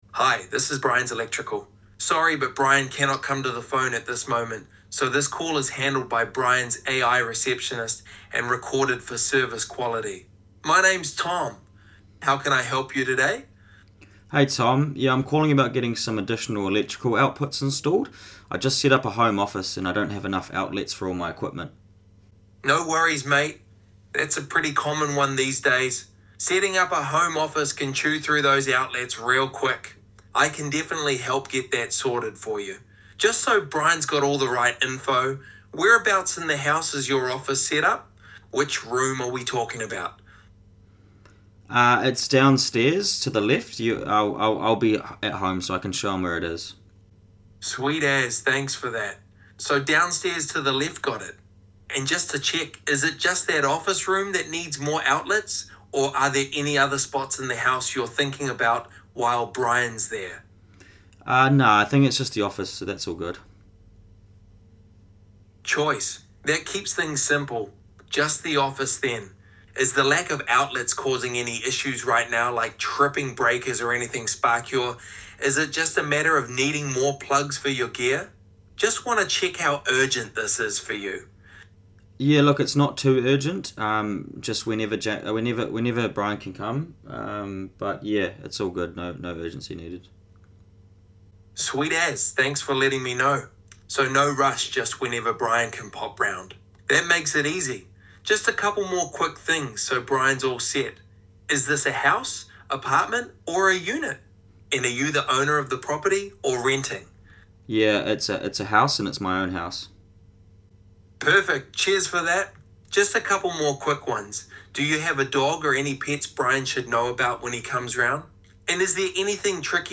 Sounds Completely Human
🎧 Customer inquiry – Power outlet installation